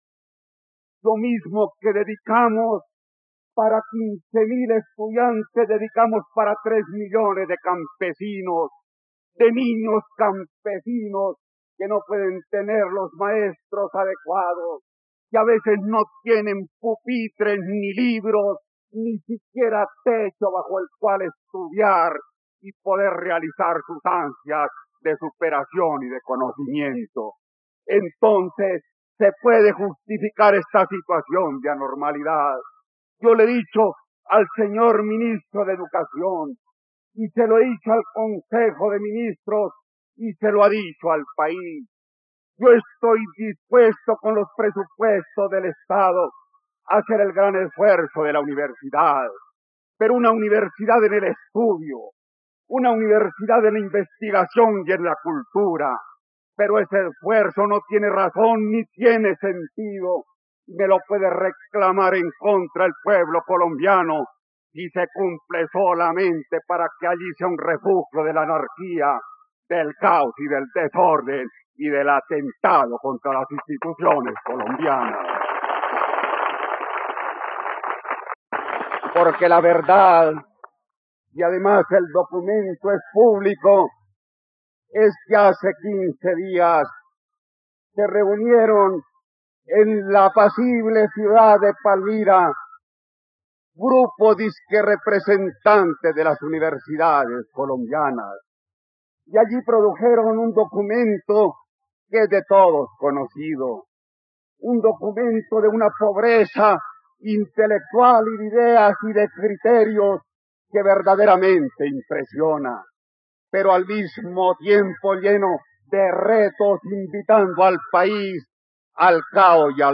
..Escucha ahora el discurso del presidente Misael Pastrana Borrero en torno a la educación, el 22 de abril de 1971, en la plataforma de streaming RTVCPlay.